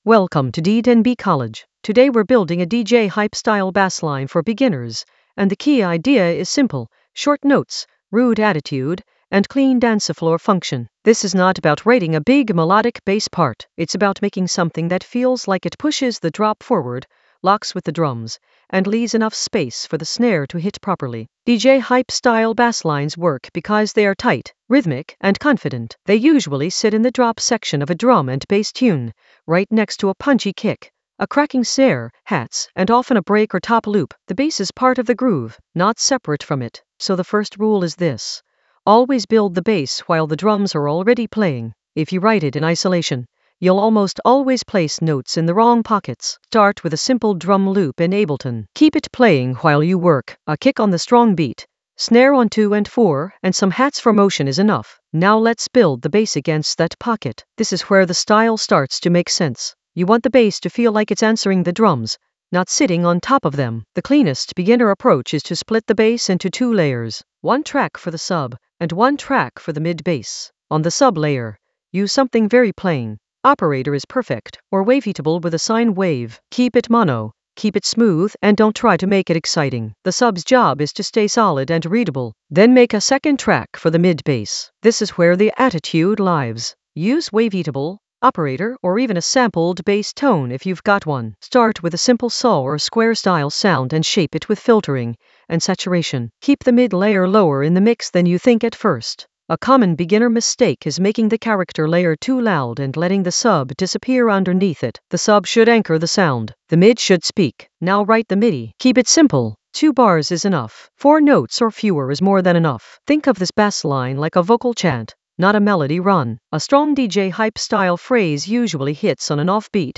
An AI-generated beginner Ableton lesson focused on DJ Hype style basslines in the Basslines area of drum and bass production.
Narrated lesson audio
The voice track includes the tutorial plus extra teacher commentary.